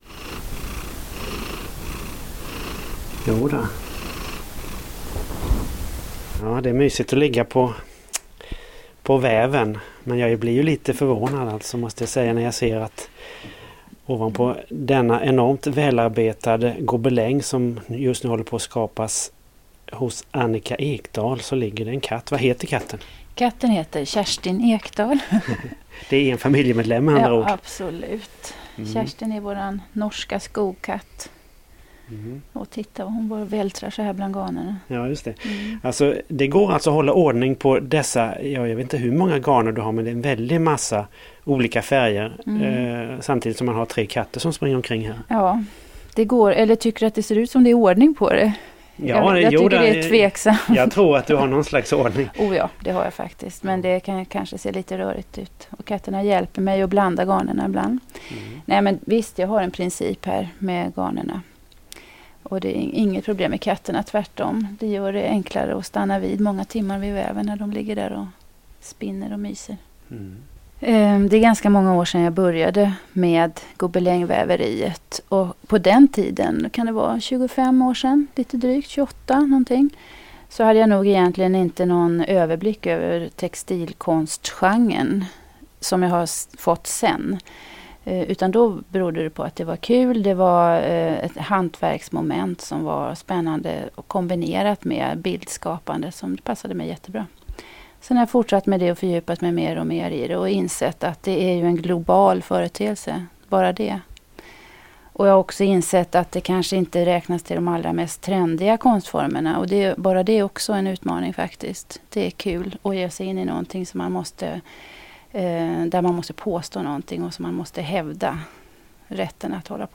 intervjuade henne hemma i Kyrkhult 1 augusti 2005. Här talar de om hur arbetet går till och om den kritik som mött hennes konst.